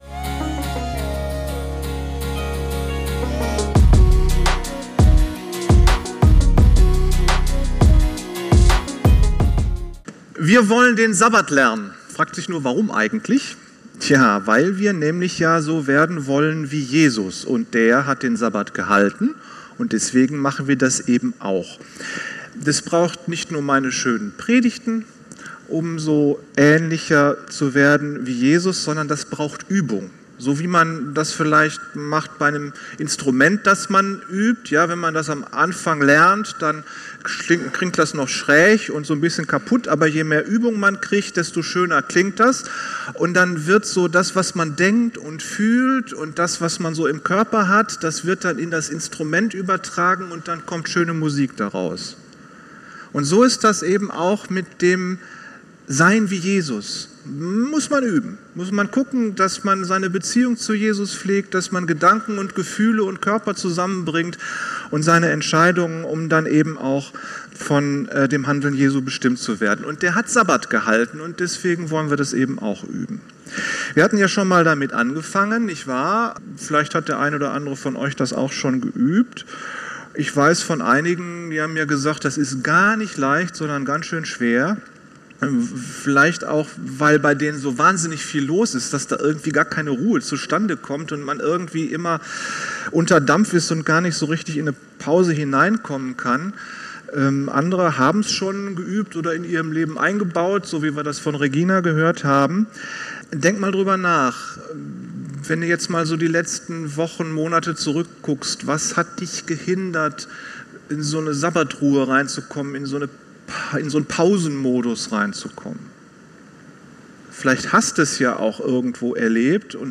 In dieser Predigt geht es um einen Aspekt, den man vermutlich erst einmal nicht mit "Sabbat" verbindet - nämlich "Genuss".